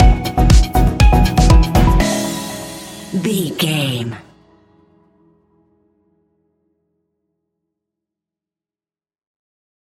Ionian/Major
house
electro dance
Fast
electronic
synths
techno
trance
instrumentals